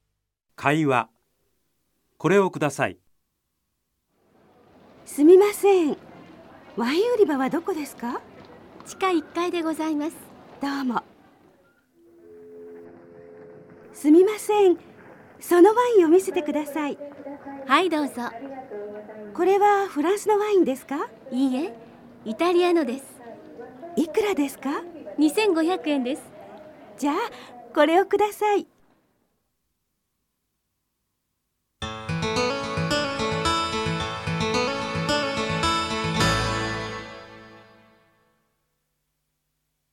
Minano Nihongo Bài 3: Hội thoại
会話